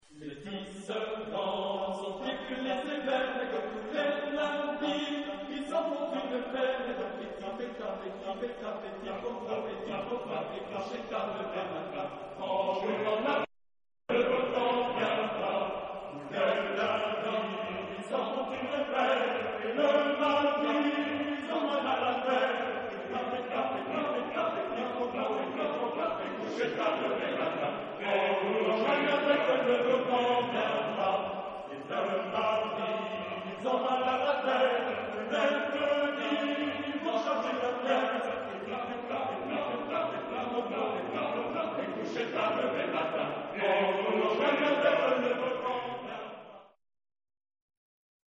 Genre-Style-Forme : Profane ; Chanson ; Populaire
Caractère de la pièce : narratif ; vivant ; satirique
Type de choeur : SATBarB  (5 voix mixtes )
Tonalité : sol majeur